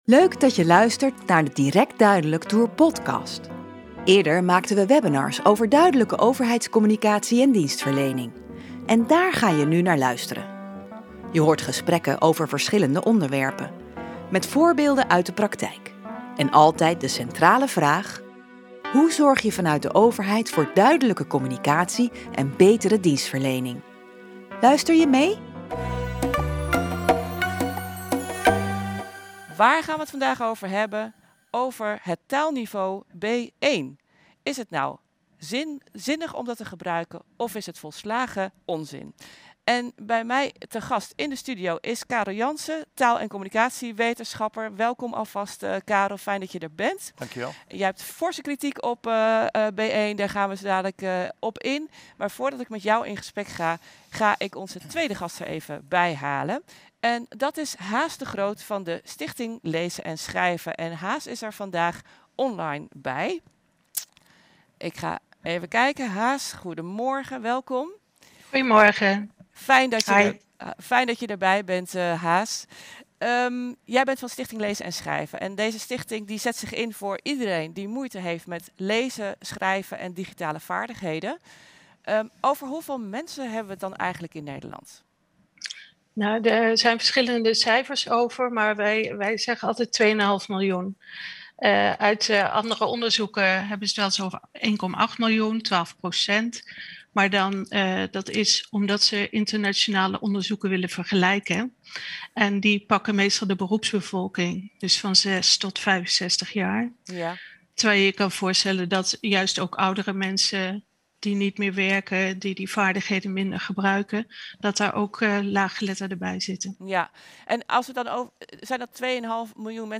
Luister dit webinar van de Direct Duidelijk Tour terug: 'De zin en onzin van B1'. Is dit taalniveau helpend of niet?